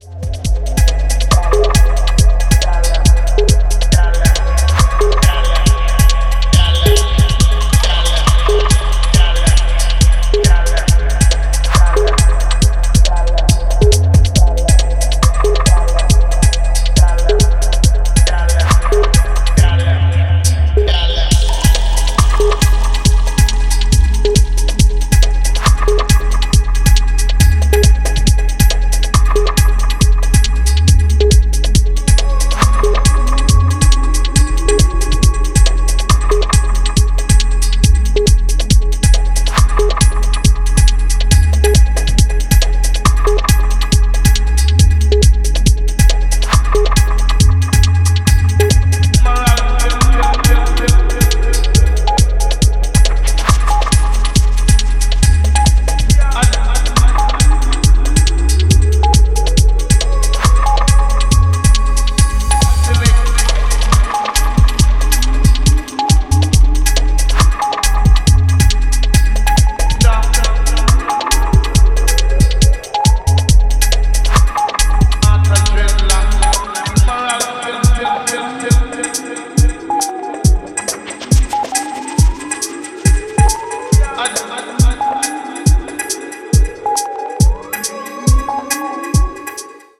伝統的ダブ・テクノの感覚を現代的なスタイルに落とし込んだ感もあり、ジャンルを跨いだフロアで作用してくれるはず。